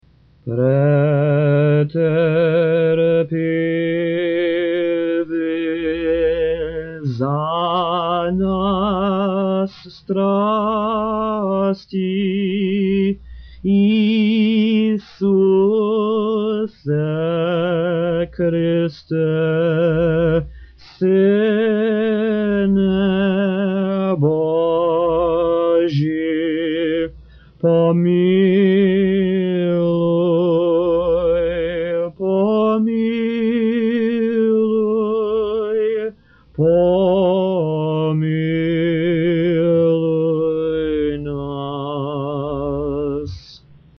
This brief prayer or paraliturgical hymn is very frequently sung immediately after services on the weekdays of the Great Fast; it is not sung on Saturdays or Sundays. It is often sung three times, in English, Slavonic or both: once by the priest, and twice more by the faithful; a prostration (a complete bow of the body with the head touching the floor) is made with each repetition of the hymn..